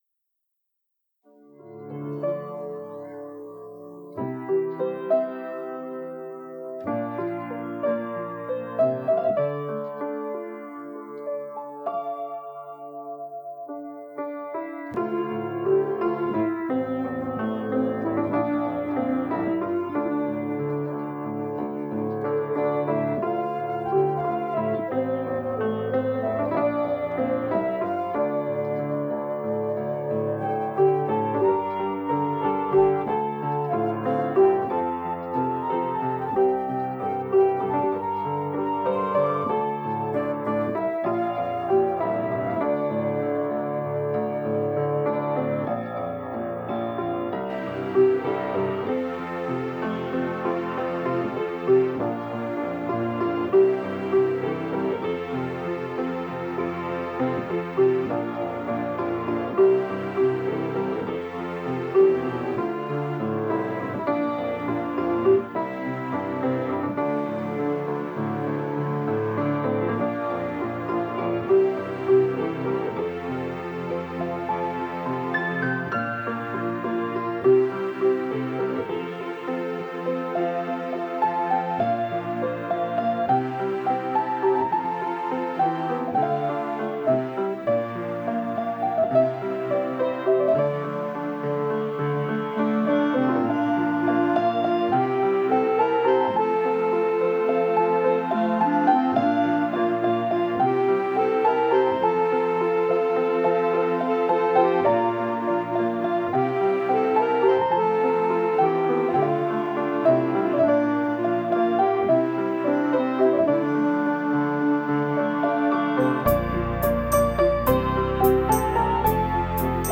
ΟΡΧΗΣΤΡΙΚΟ